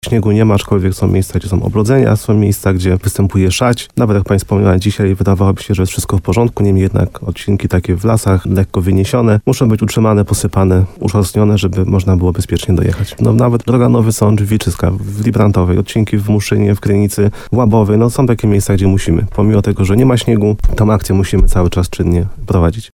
Nawet droga Nowy Sącz – Wilczyska , w Librantowej , odcinki w Muszynie, Krynicy czy Łabowie trzeba akcje czynnie prowadzić, pomimo tego, że nie ma śniegu – mówi dyrektor w programie Słowo za Słowo na antenie RDN Nowy Sącz.